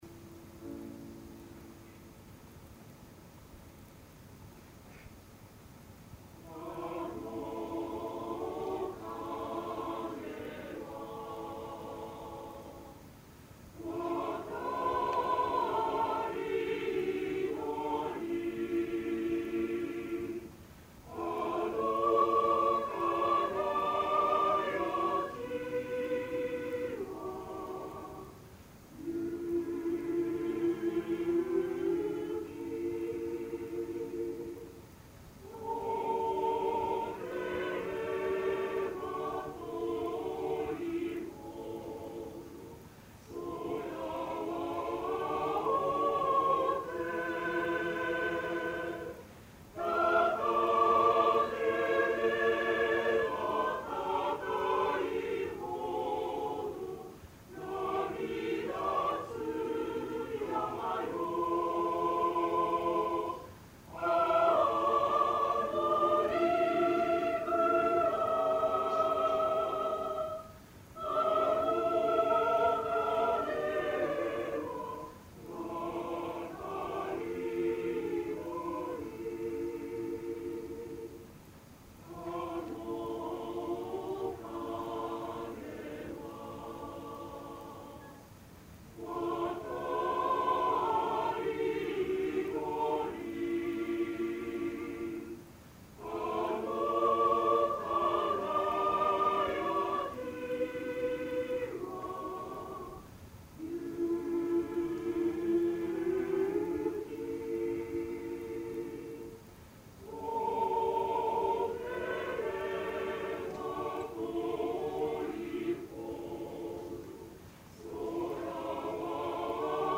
１９９９年６月１３日　東京・中野ゼロホールで
ソプラノ５名、アルト５名、テナー４名、ベース３名の
編成です。男性１名カウンターテナーがアルトです。
お母様が小さなラジカセでテープ録音をして頂き、
音量、音質はご勘弁下さい。
アカペラコーラス,この合唱団のテーマソング
合唱団　某大学ＯＢ会合唱団メンバー　１７名